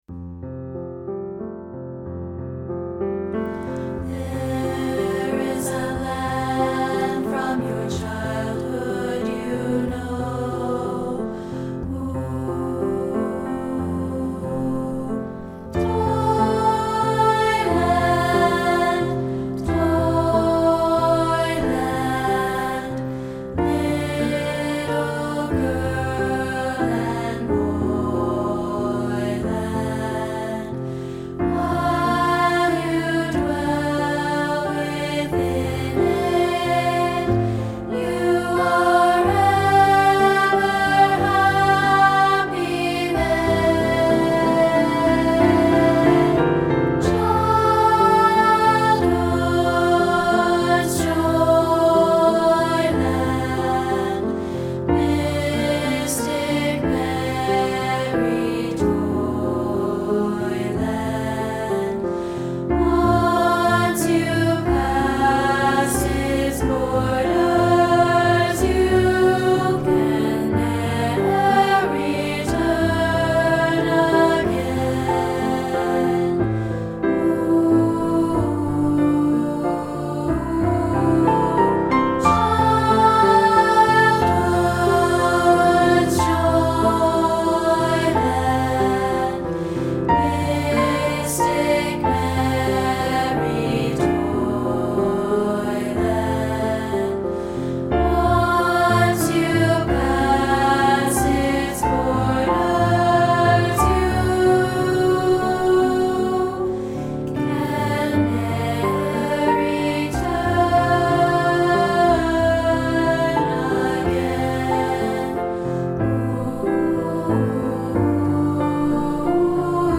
Here's a rehearsal track of part 2, isolated.